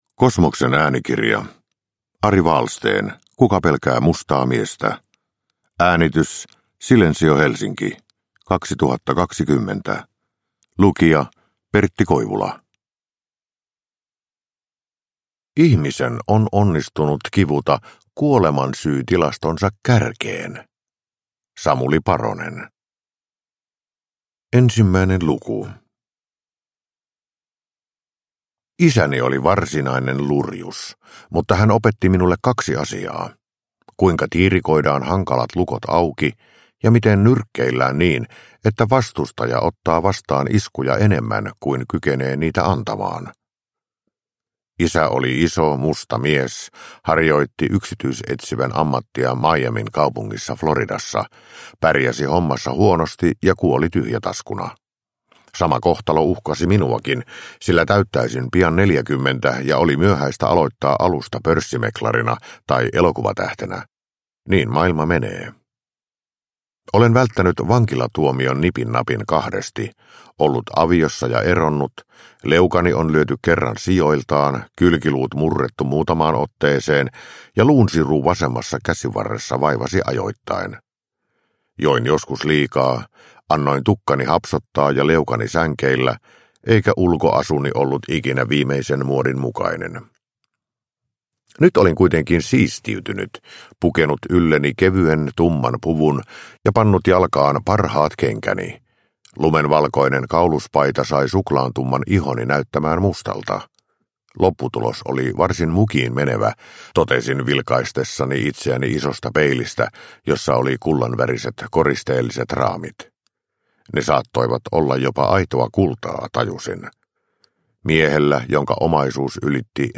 Kuka pelkää mustaa miestä? – Ljudbok – Laddas ner
Uppläsare: Pertti Koivula